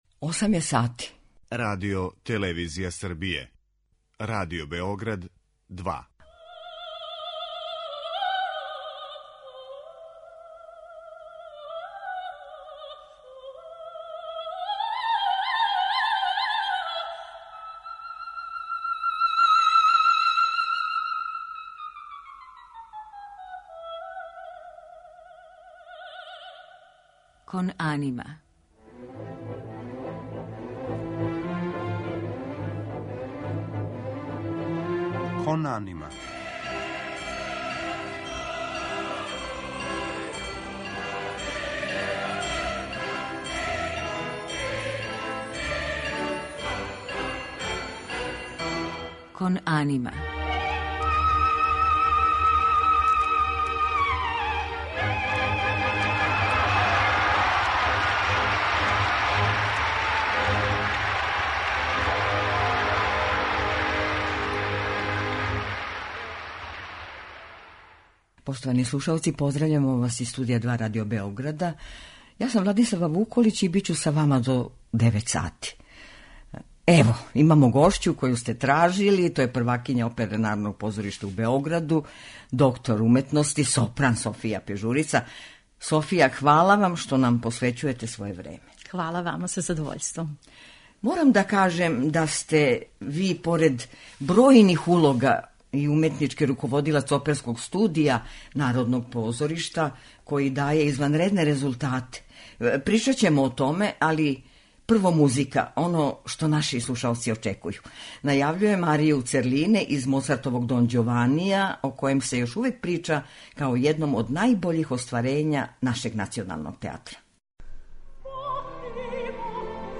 У музичком делу биће емитоване арије из опера Моцарта, Вердија, Доницетија и Пучинија у извођењу ове наше еминентне оперске уметнице.